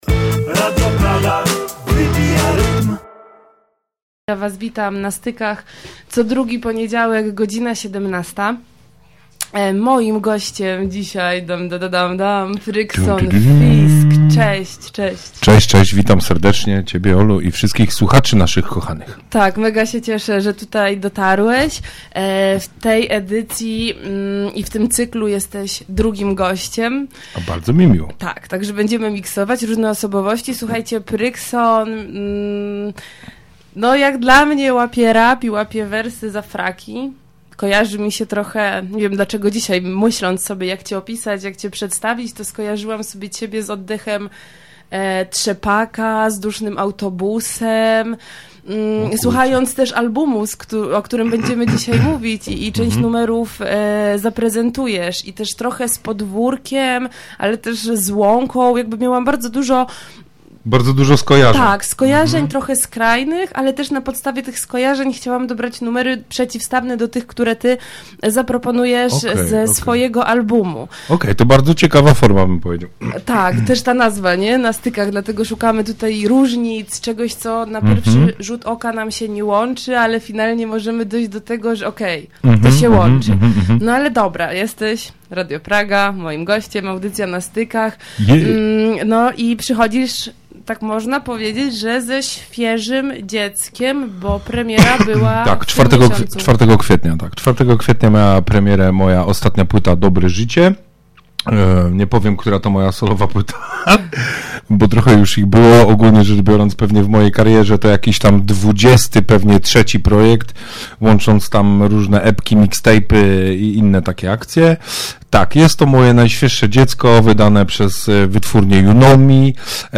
W audycji „Na Stykach” rozłożyliśmy ten materiał na czynniki pierwsze.